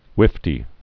(wĭftē)